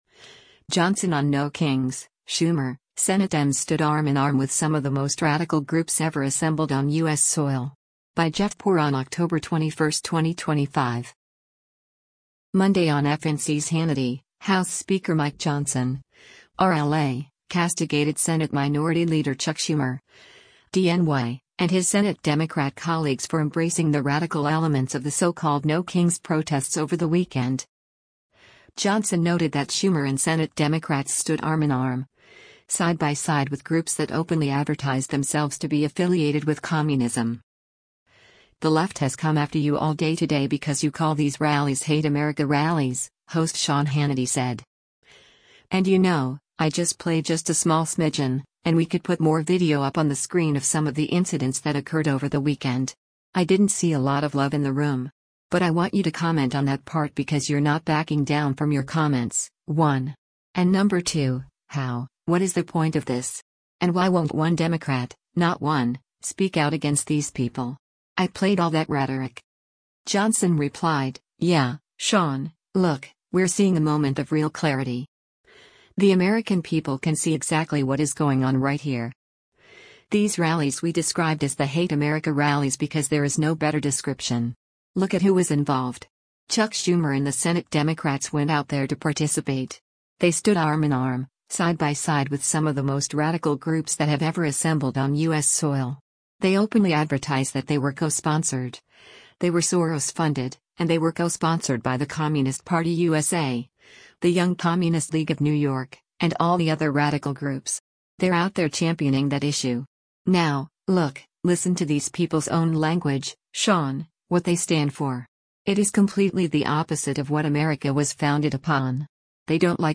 Monday on FNC’s “Hannity,” House Speaker Mike Johnson (R-LA) castigated Senate Minority Leader Chuck Schumer (D-NY) and his Senate Democrat colleagues for embracing the radical elements of the so-called “No Kings” protests over the weekend.